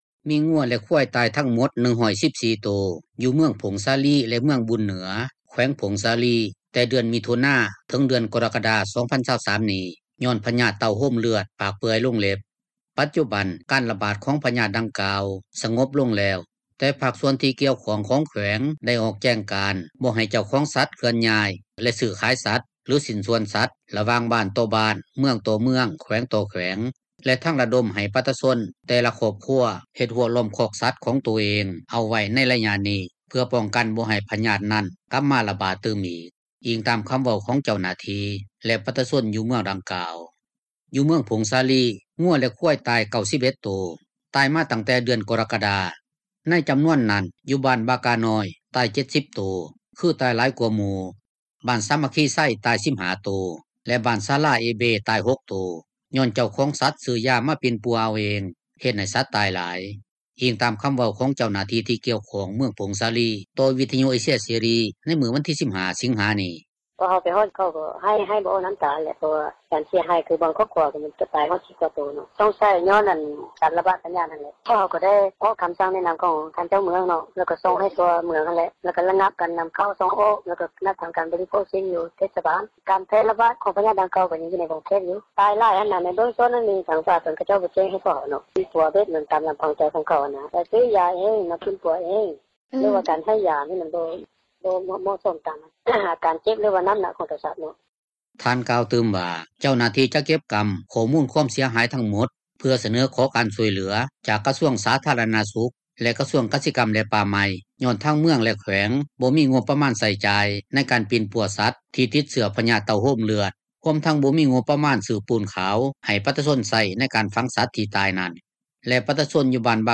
ດັ່ງເຈົ້າໜ້າທີ່ ປົກຄອງທ້ອງ ຖິ່ນທ່ານນຶ່ງກ່າວ ໃນມື້ວັນທີ 15 ສິງຫານີ້ວ່າ:
ດັ່ງເຈົ້າໜ້າທີ່ ທີ່ກ່ຽວຂ້ອງແຂວງຜົ້ງສາລີ ເວົ້າໃນມື້ດຽວກັນນີ້ວ່າ: